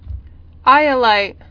[i·o·lite]